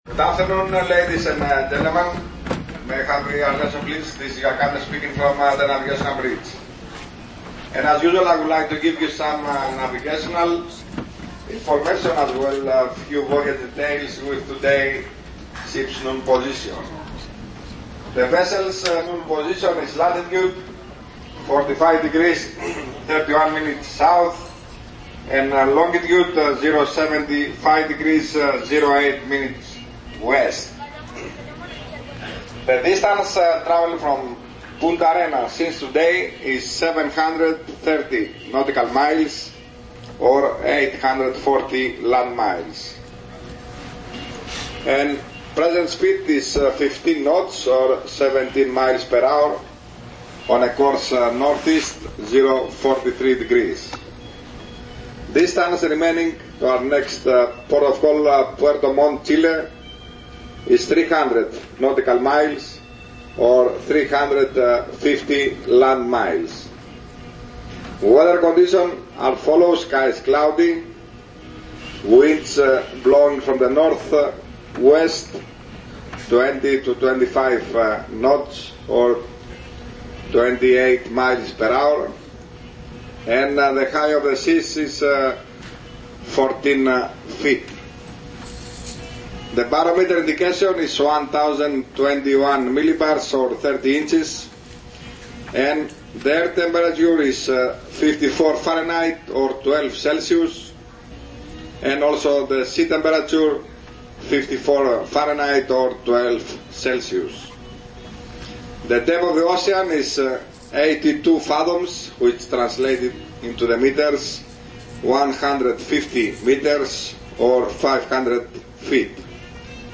Voici un communiqué
Attention: anglicistes puristes, passez votre chemin car vous risquez de vous retrouver en arrêt maladie pour très longtemps.